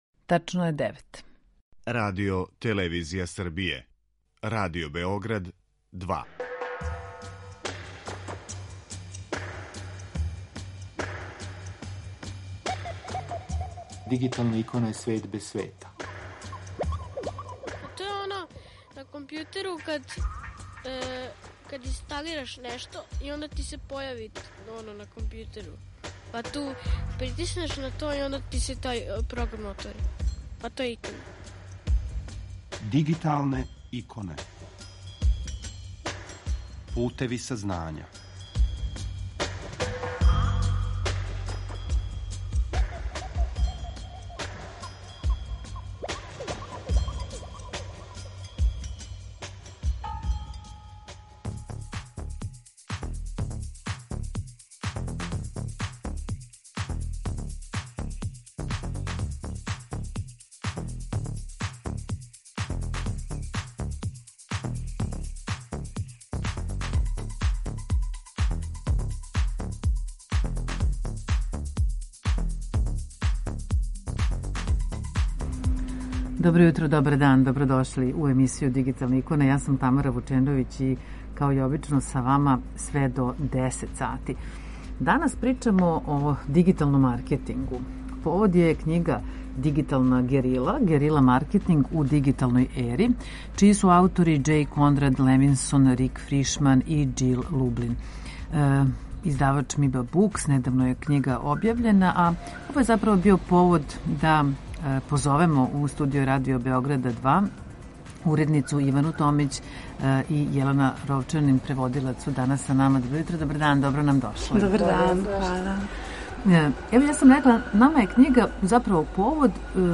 У данашњој емисији Дигиталне иконе разговарамо о дигиталном маркетингу, а повод је књига Дигитална герила - герила маркетинг у дигиталној ери чији су аутори Џеј Kонрад Левинсон, Рик Фришман и Џил Лублин. Са нама у студију Радио Београда 2